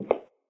inside-step-2.ogg.mp3